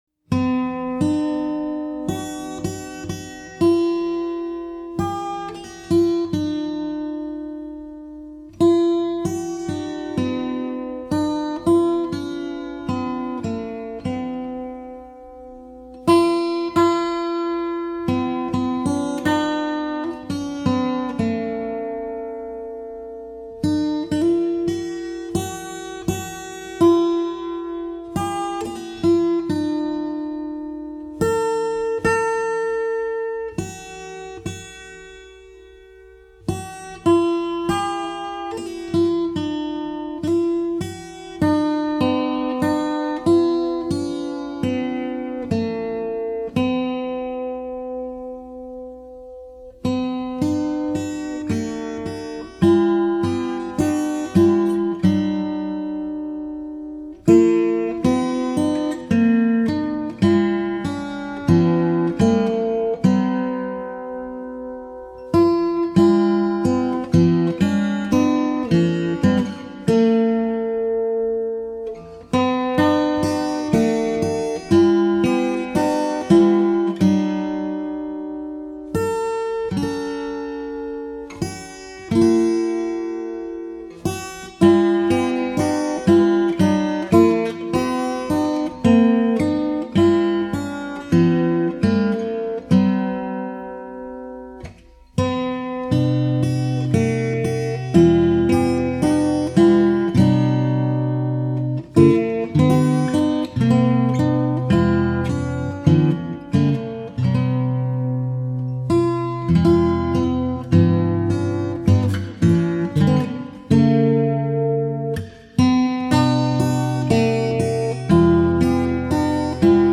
FINGERPICKING SOLO Christmas, Holiday, Guitar Solos
Dropped D tuning DADGBE